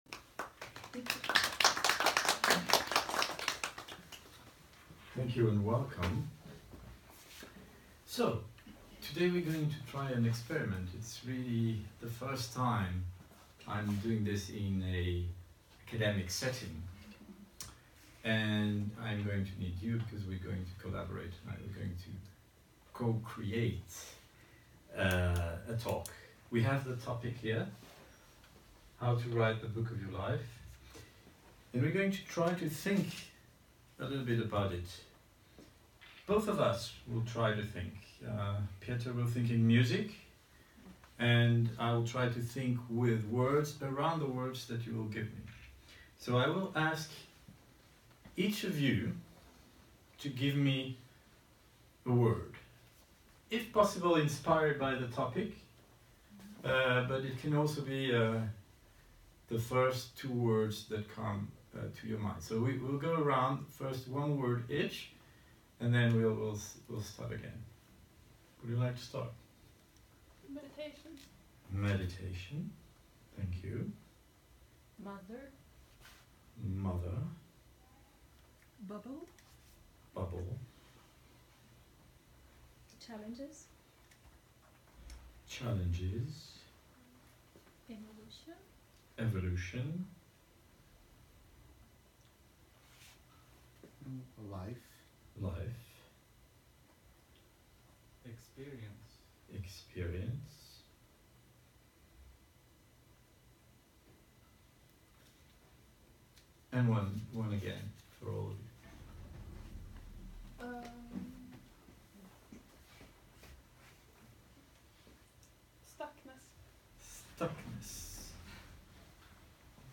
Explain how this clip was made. An improvised conference around the words offered by the audience at Stockholm University.